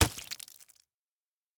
sounds / mob / drowned / step5.ogg
step5.ogg